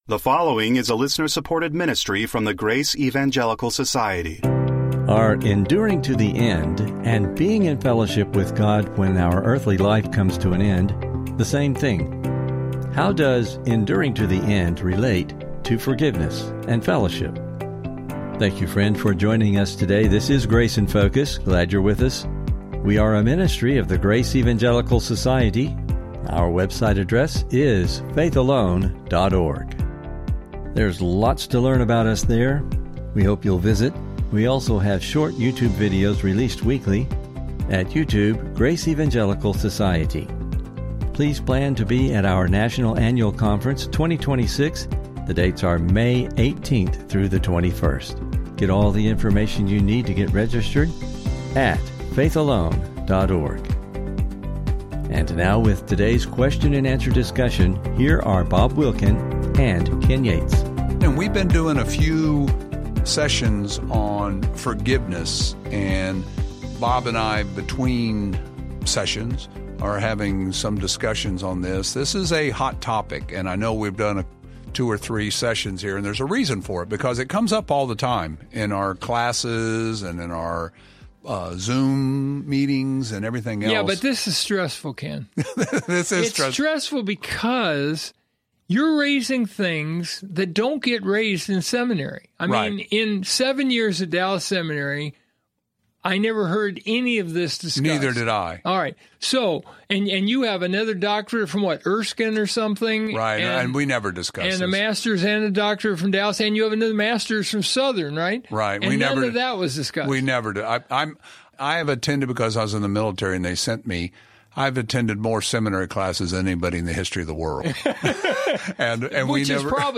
Please listen for an informative discussion and never miss an episode of the Grace in Focus Podcast!